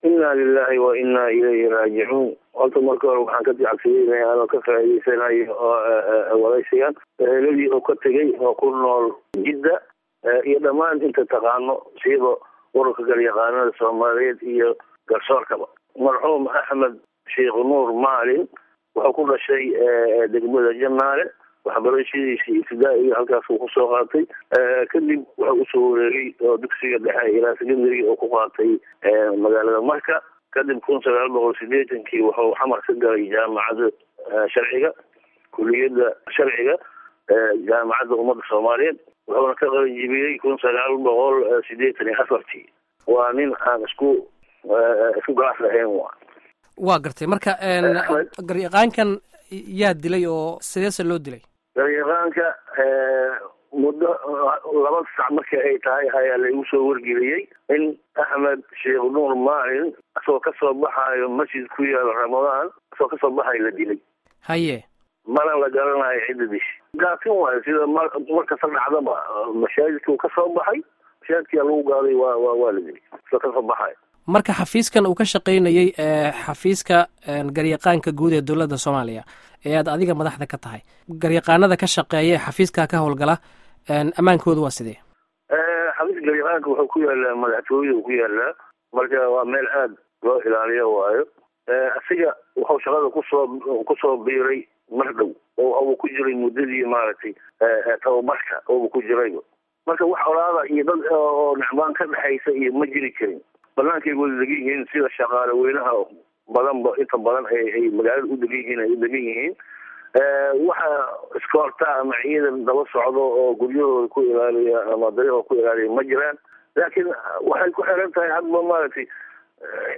Dhageyso Wareysiga Garyaqaanka Guud